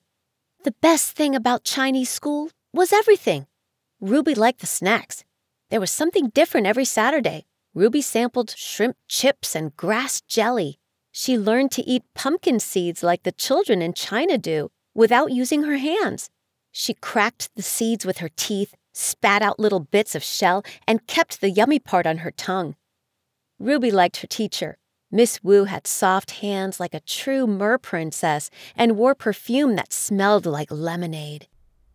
Female
English (North American)
Adult (30-50), Older Sound (50+)
Children'S Audiobook Sample
0501Children_sNovelSample.mp3